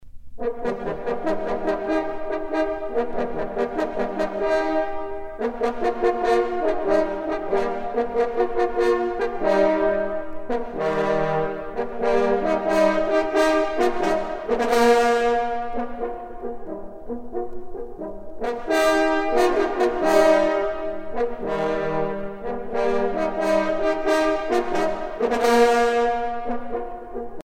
vénerie
Pièce musicale éditée